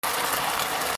フレンチトーストを焼く 02
料理